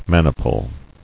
[man·i·ple]